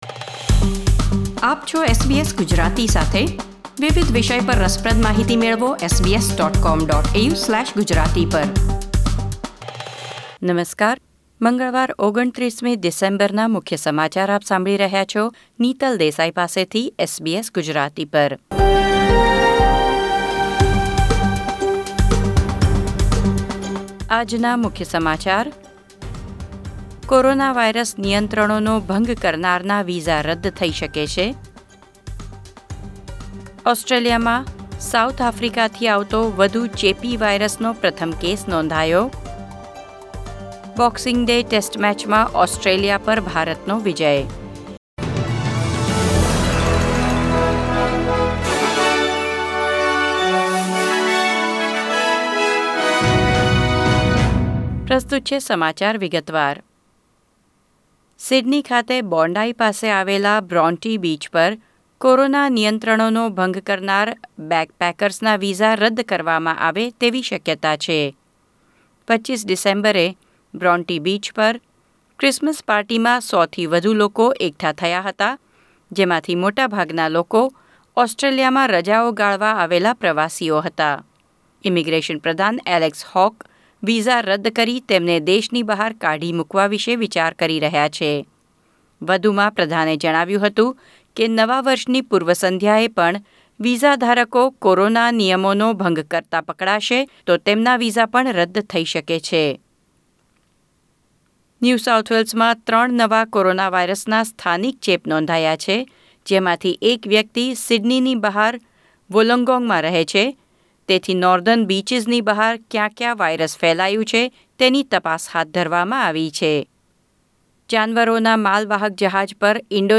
SBS Gujarati News Bulletin 29 December 2020